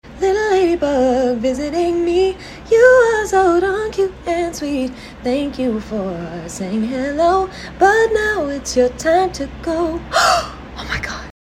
a little improvised lady bug 11 seconds 24 Downloads The hills are alive!!